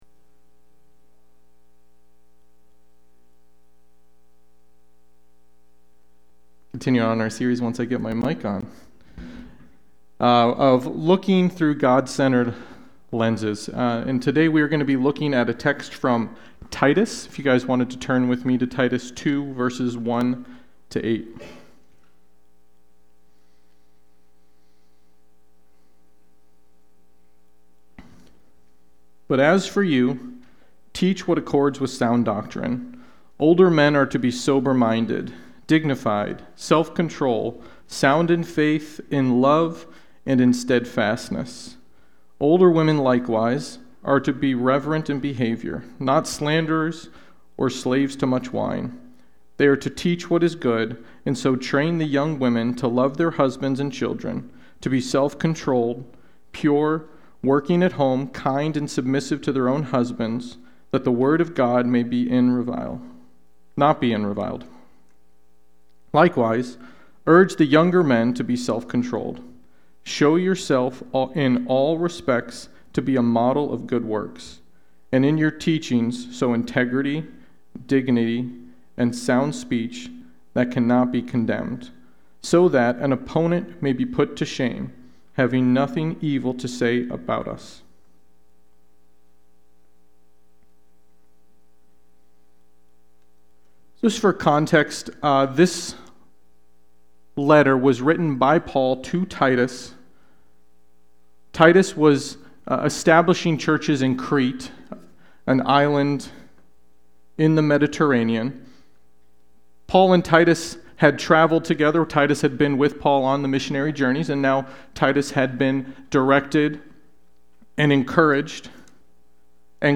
Sunday-Worship-main-52624.mp3